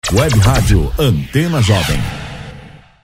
Masculino
Voz Jovem